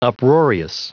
Prononciation du mot uproarious en anglais (fichier audio)
Prononciation du mot : uproarious